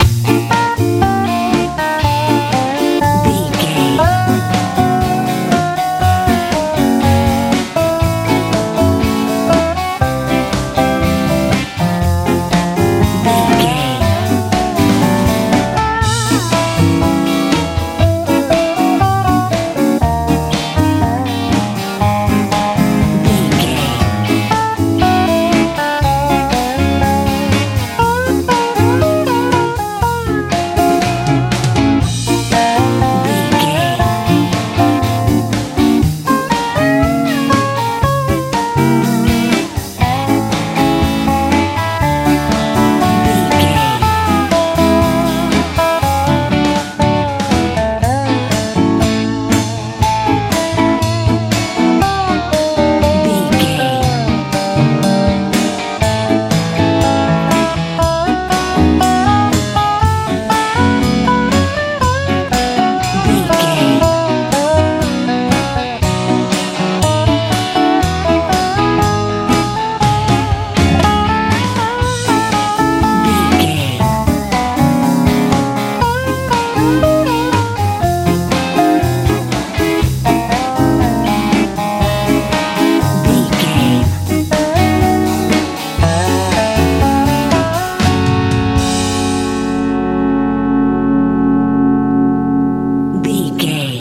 Ionian/Major
A♯
bright
reflective
bass guitar
electric guitar
drums
energetic
driving